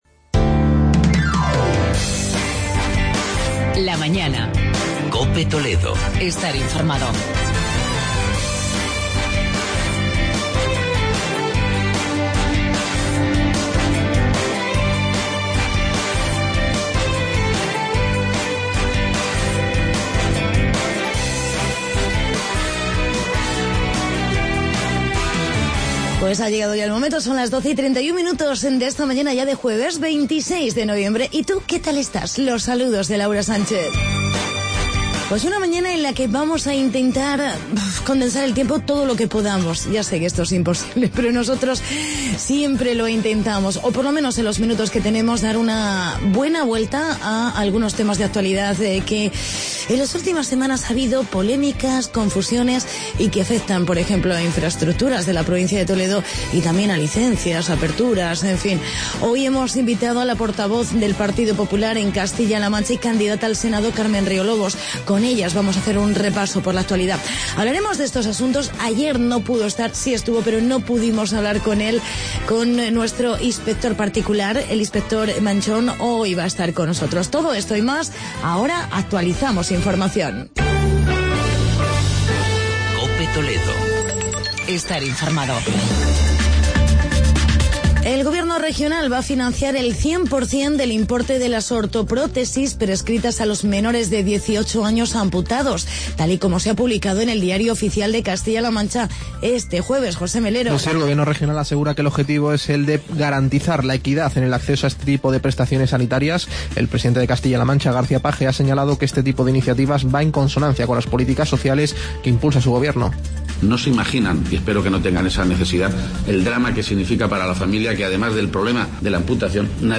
Entrevista con Carmen Riolobos